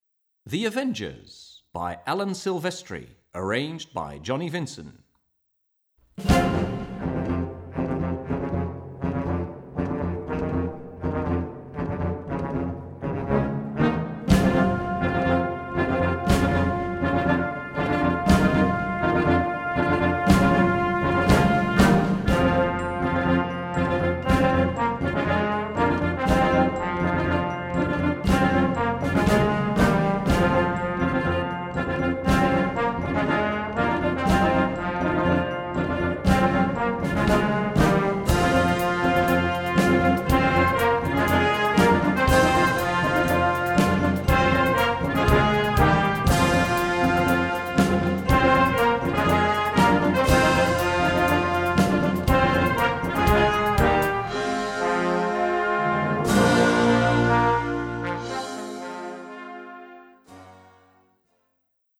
Gattung: Flex Band (5-stimmig)
Besetzung: Blasorchester